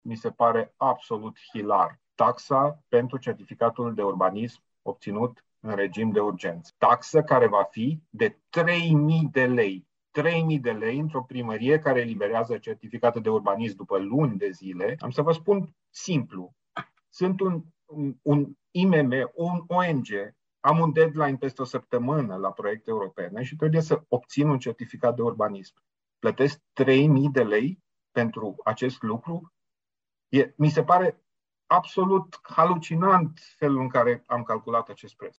Fostul viceprimar Dan Diaconu, consilier PNL, a reclamat că taxa pentru eliberarea unui certificat de urbanism în regim de urgență este foarte mare: